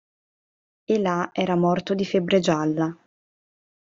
Read more there Frequency A1 Pronounced as (IPA) /ˈla/ Etymology From Latin illāc.